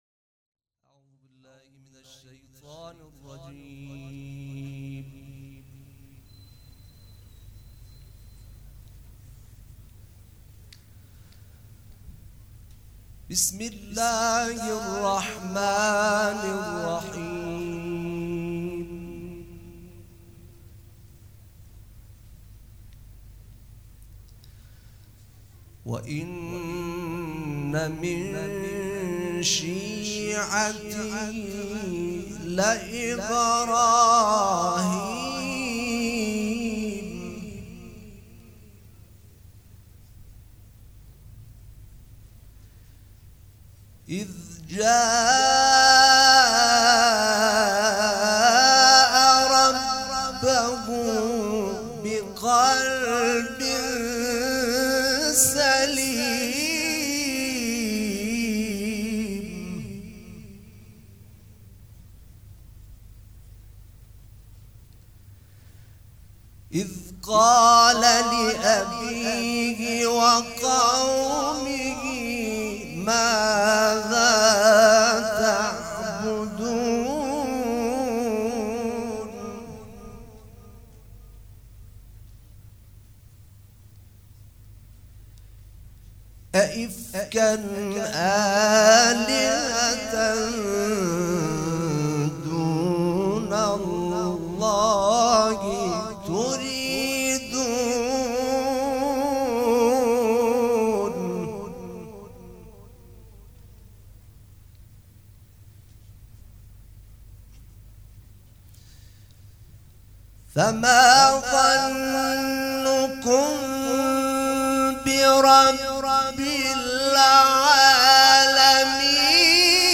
دوشنبه 29 مرداد 1397 هیئت ریحانه الحسین سلام الله علیها
سبک اثــر قرائت قرآن
شهادت حضرت مسلم علیه السّلام